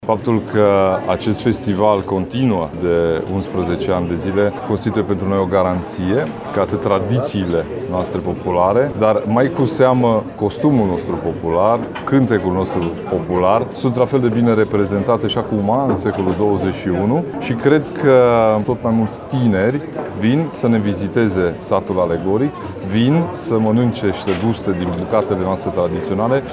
Cea de-a XI-a ediţie a Festivalului Văii Mureşului a avut loc sâmbătă şi duminică la Răstoliţa, pe platoul La Alei.
Preşedintele CJ Mureş, Ciprian Dobre.